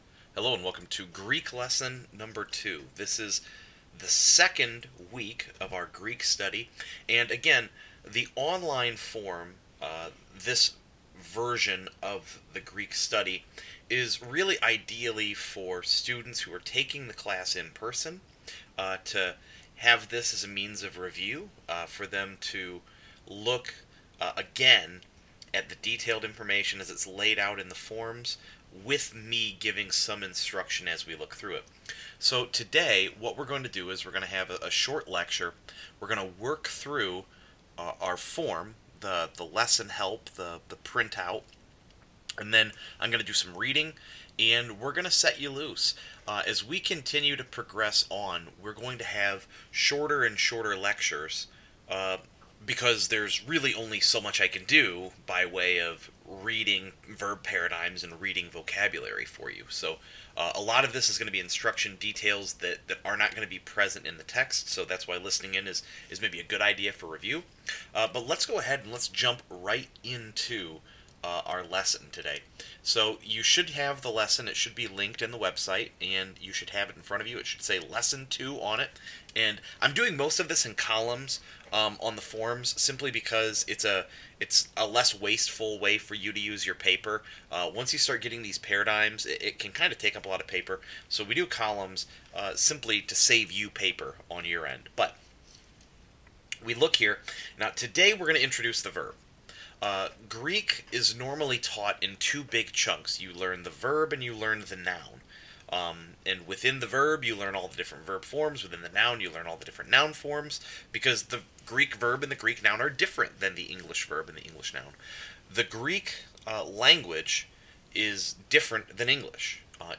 Sermons Archive
Greek Lesson 2